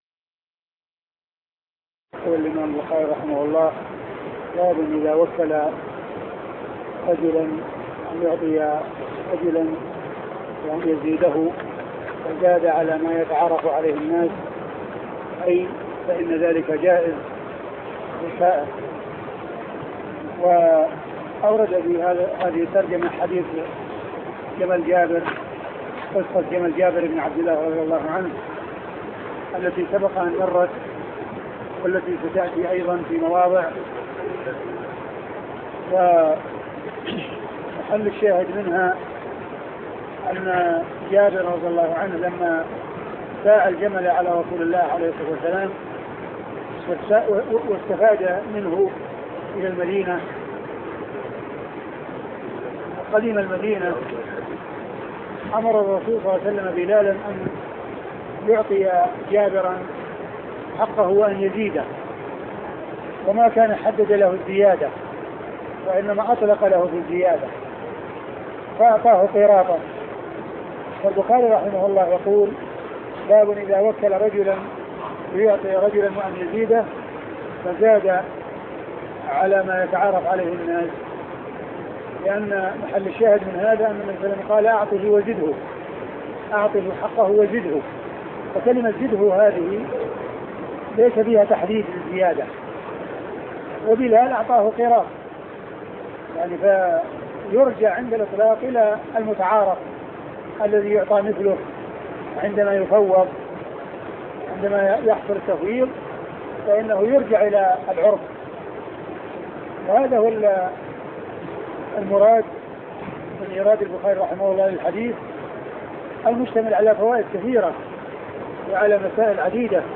شرح صحيح البخاري الدرس عدد 270